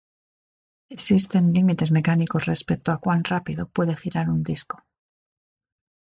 Read more Noun Verb discar to dial Read more Frequency B1 Hyphenated as dis‧co Pronounced as (IPA) /ˈdisko/ Etymology Borrowed from Latin discus Cognate with English disc In summary Short for discoteca.